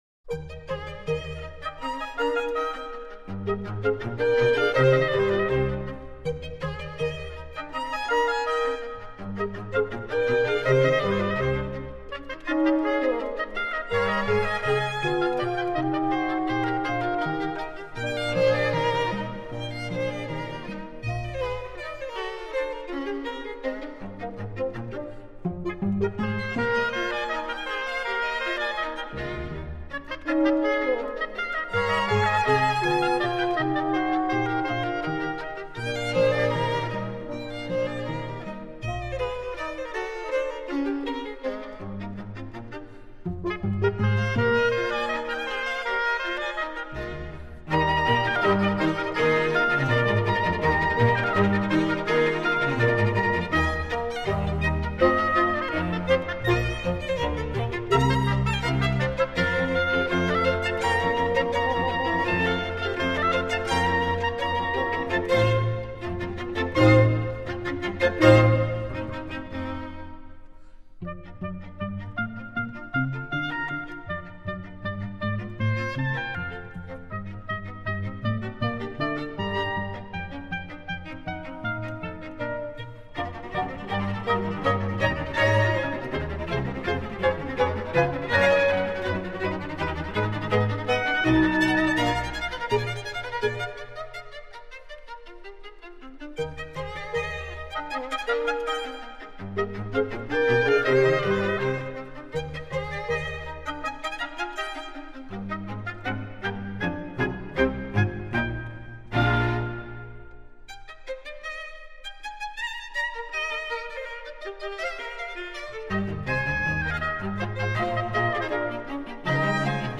Piano
violin
viola
cello
double bass
oboe
bassoon
horn
harp